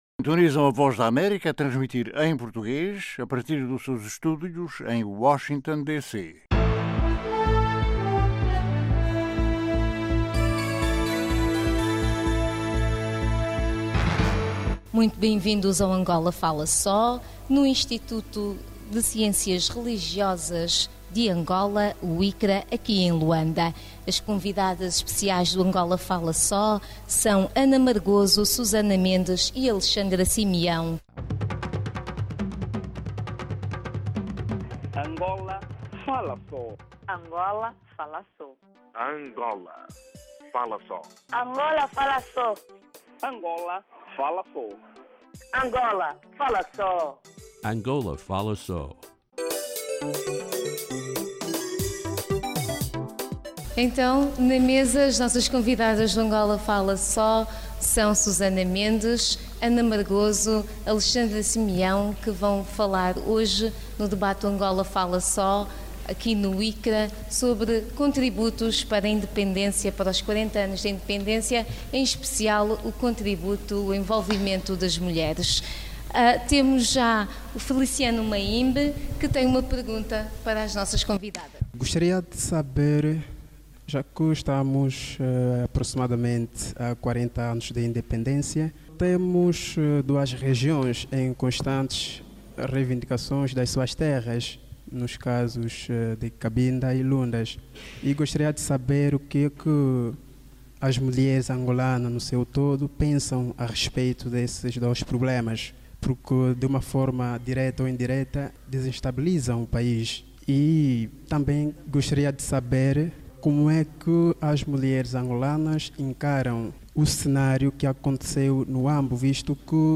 16 Out 2015 AFS em Luanda - Debate aborda diversos temas, inclusive violência contra as zungueiras